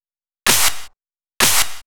VTDS2 Song Kit 05 Male Running In Cirlces Clapsnare.wav